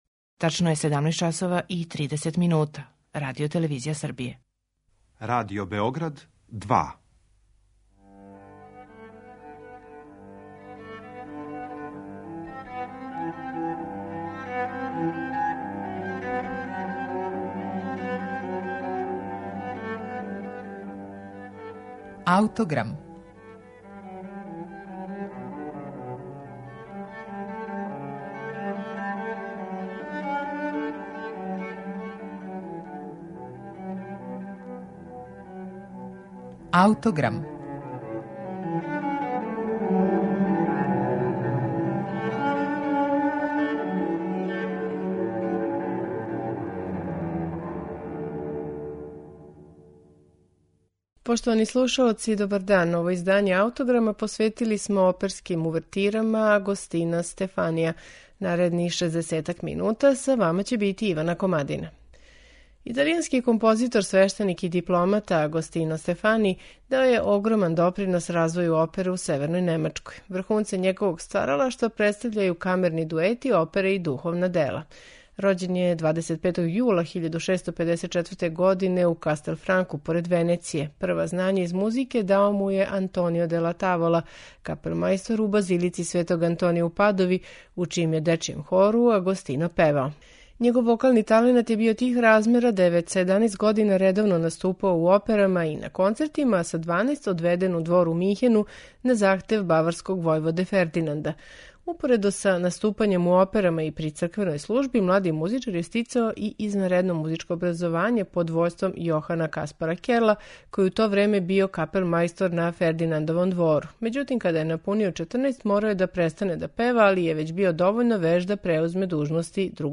За данашњу емисују издвојили смо увертире и оркестарске интерлудијуме из опера италијанског барокног аутора Агостина Стефанија.
Слушаћете их у интерпретацији ансамбла И барокисти , под управом Дијега Фазолиса.